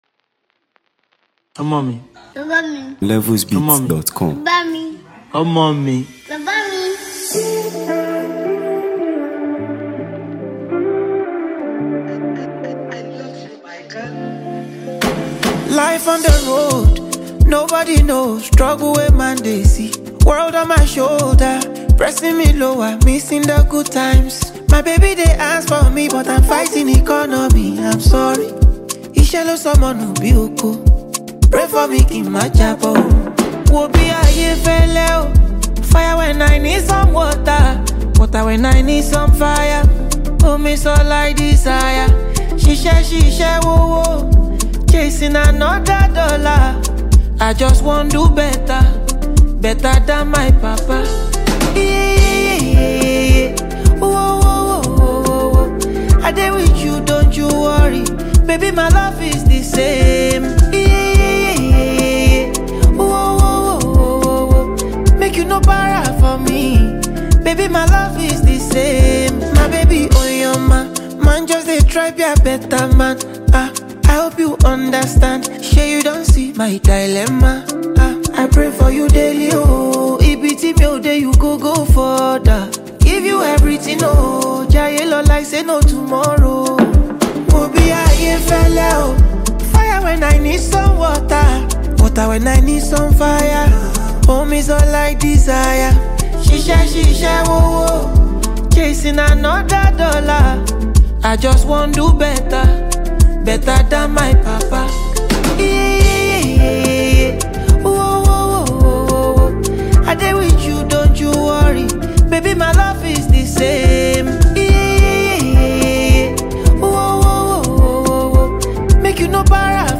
Nigeria Music
Afrobeats